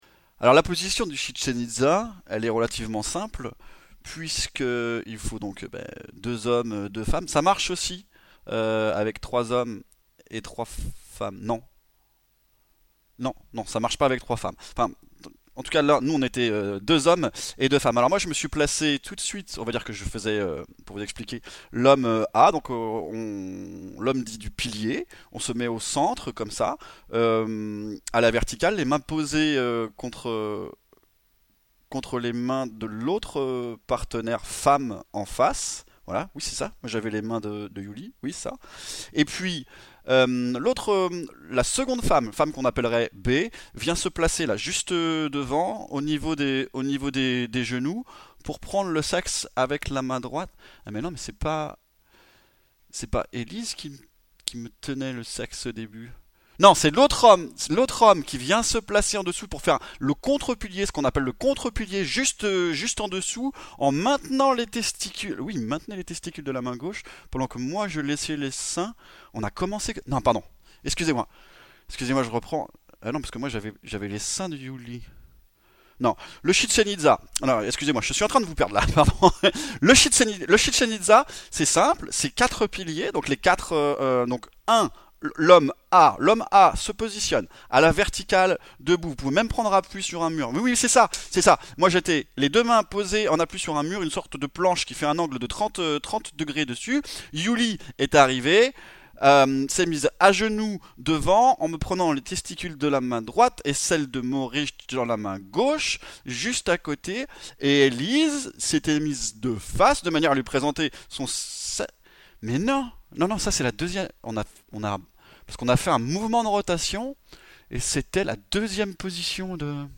Des fragments de "théâtre improvisé" immatériels, basés sur les relations textuelles.